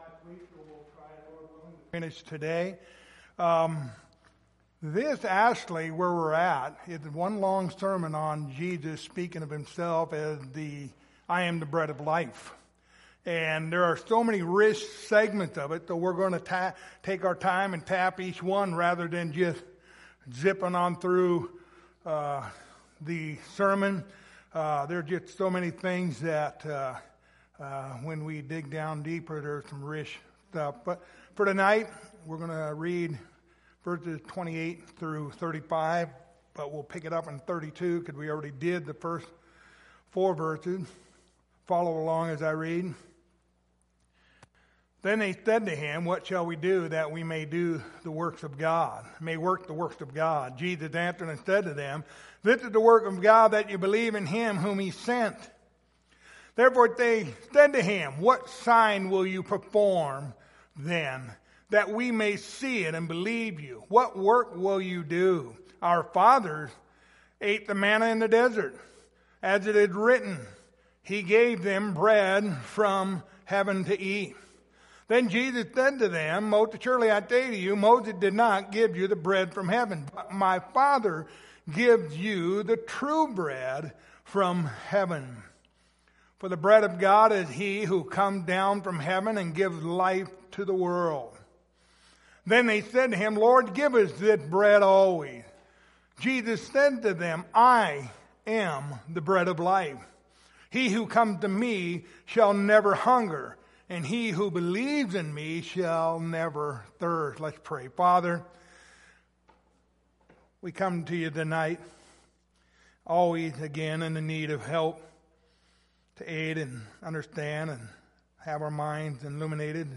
Passage: John 6:32-35 Service Type: Wednesday Evening